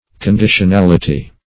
Conditionality \Con*di`tion*al"i*ty\, n.